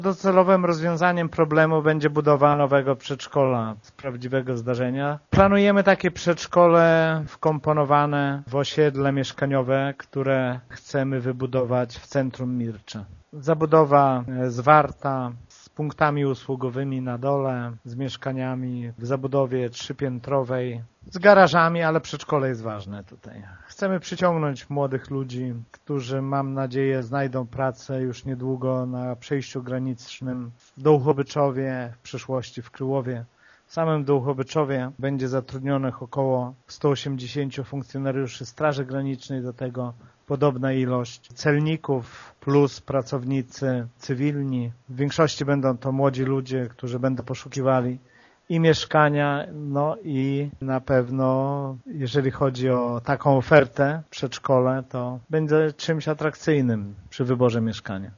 „Wprawdzie na razie miejsc dla maluchów wystarczy, ale nie jest to docelowe rozwiązanie” – przyznaje wójt Szopiński: